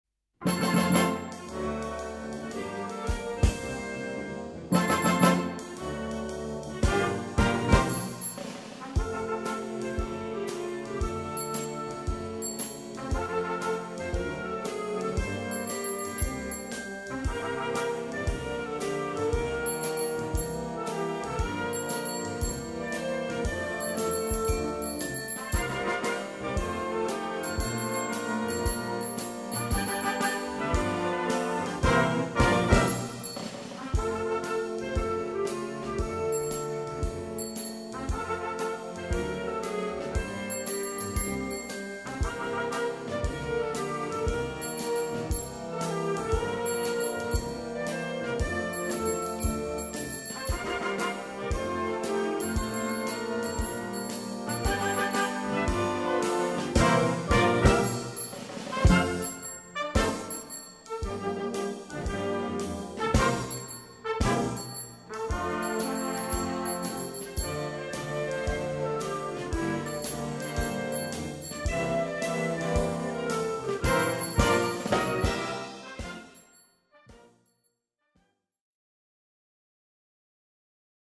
Gattung: Solostück für Fahrradklingel und Blasorchester
Besetzung: Blasorchester